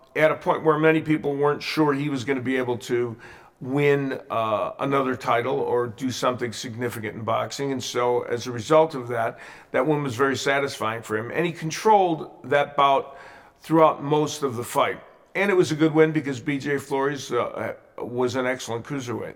Работаю над небольшим док.фильмом, прислали OMF, а там один из микрофонов "двоит".
Но похоже, что писали петличку и бум, а потом всё это криво склеели.